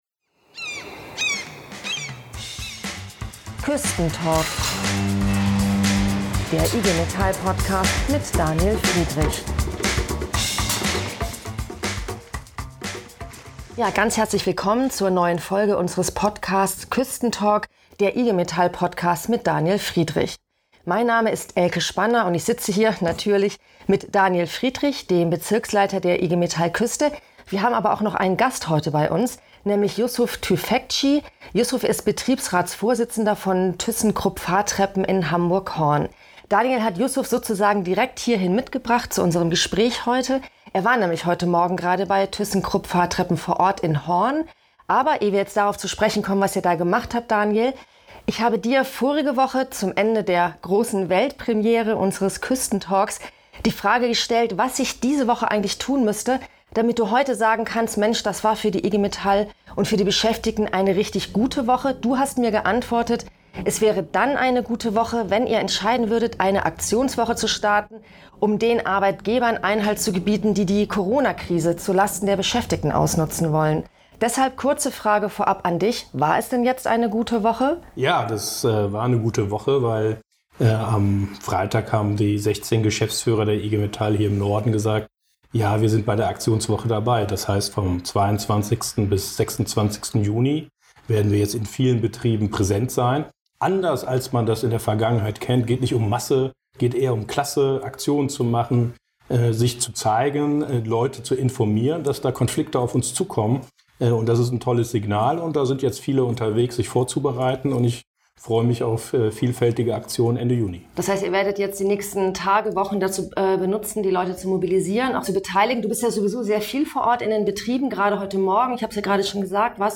Küsten-Talk: Ein Besuch bei Thyssenkrupp Fahrtreppen - wenn wir kämpfen, ist alles möglich